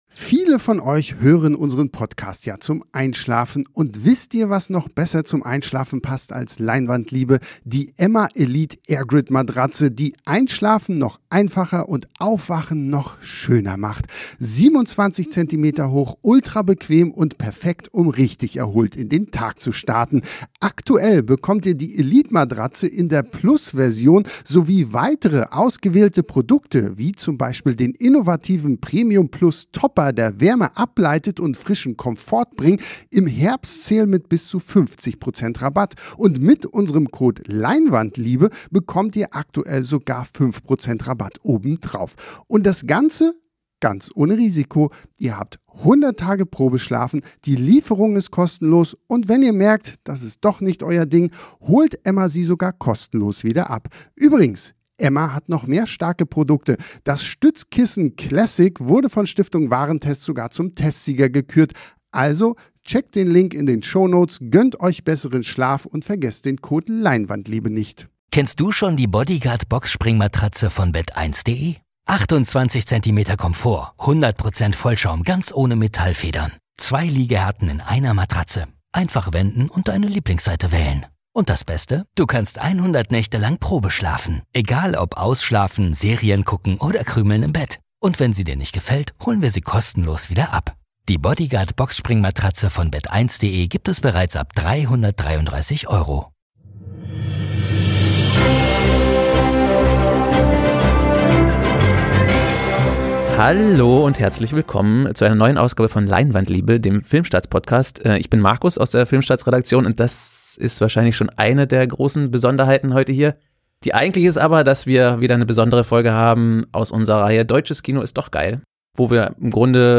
Im Podcast-Interview spricht sie mit uns ausführlich darüber, wie sie um das schwere Thema einen hoffnungsvollen Film gestrickt hat.